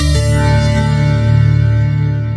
原声吉他采样
描述：这是一个原声吉他采样，通过renoise多重采样。
标签： UI 菜单 用户界面 元素 通知 成就 sfx 未来 能源 音效 数字 科幻 抽象 水平 音效中 向上 升级 音效设计 声音 按键 游戏
声道立体声